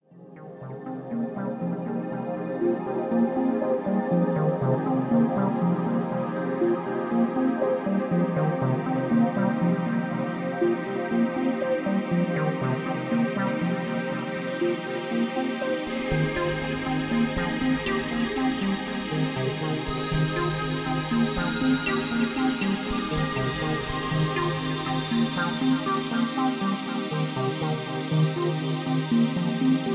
Tags: cerebral